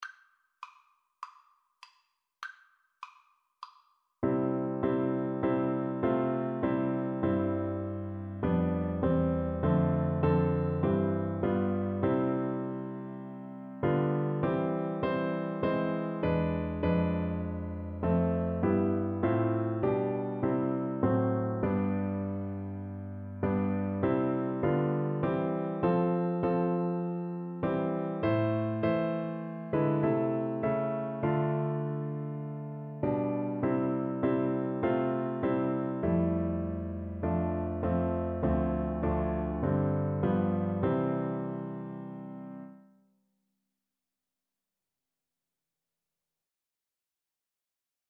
C major (Sounding Pitch) (View more C major Music for Trombone )
4/4 (View more 4/4 Music)